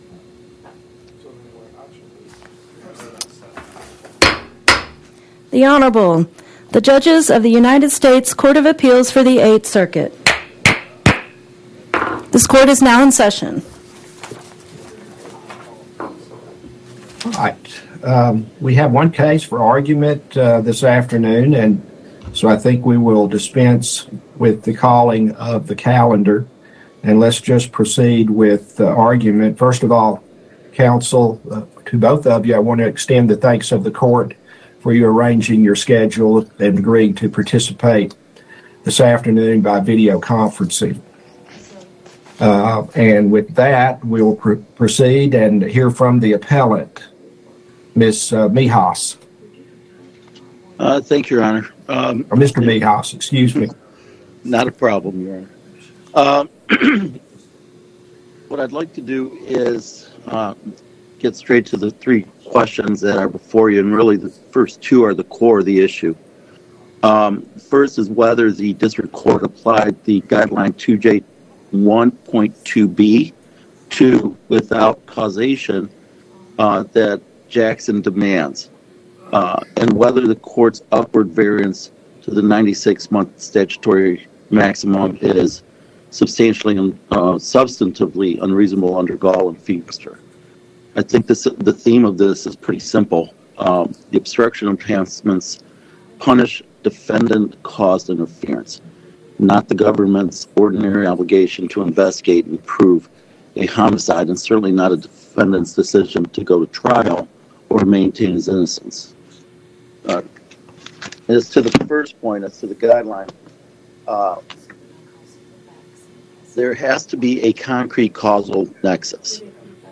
Oral argument argued before the Eighth Circuit U.S. Court of Appeals on or about 03/17/2026